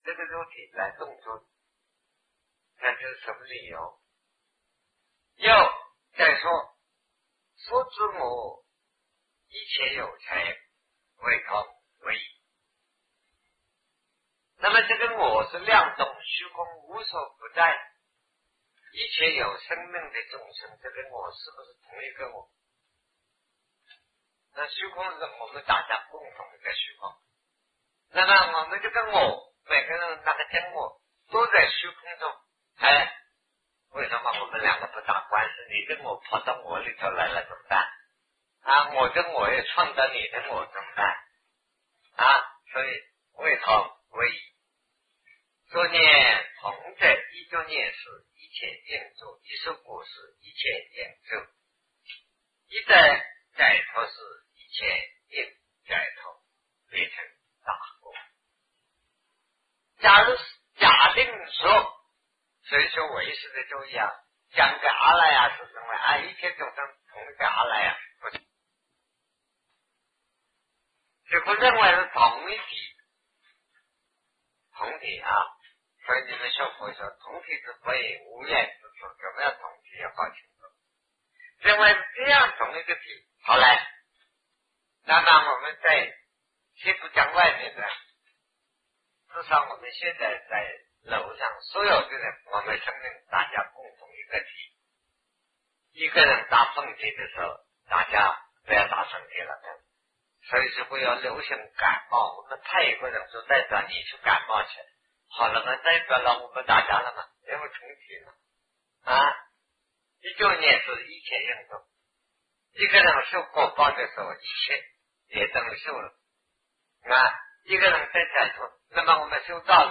本体执我略有三种 南师讲唯识与中观（1981于台湾051(下)